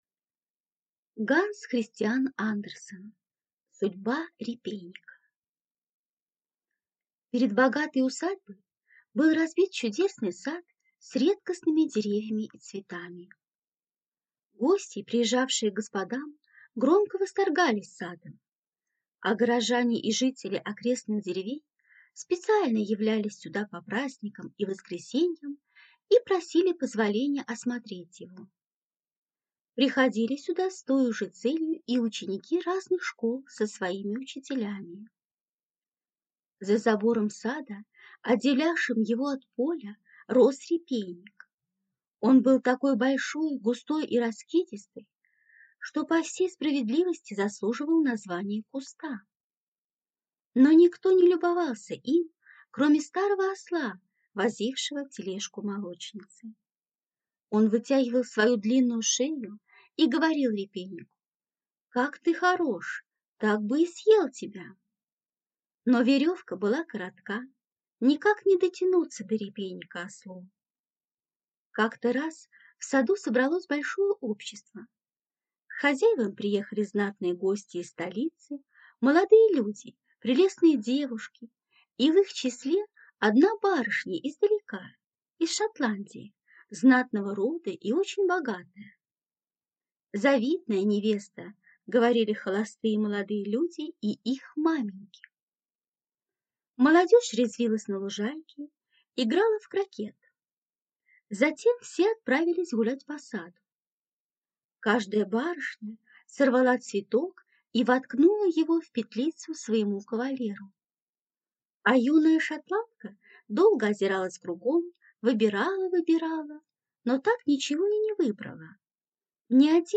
Аудиокнига Судьба репейника | Библиотека аудиокниг